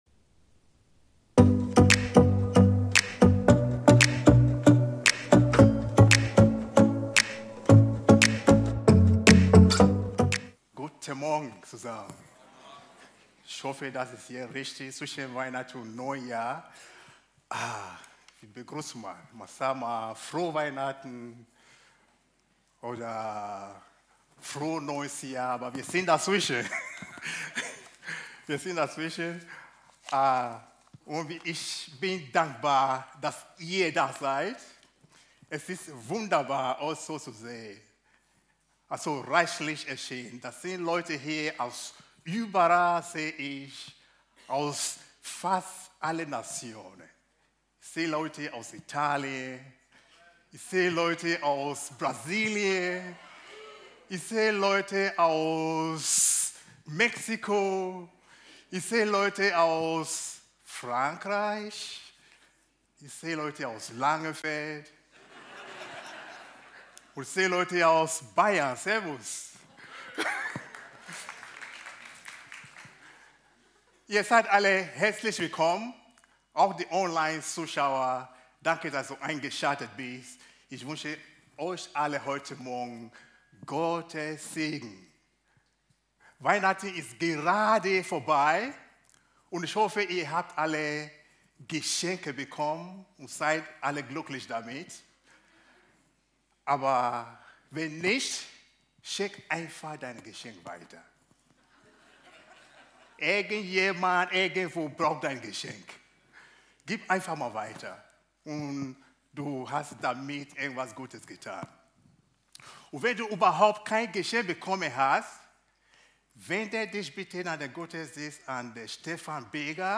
Video und MP3 Predigten
Kategorie: Sonntaggottesdienst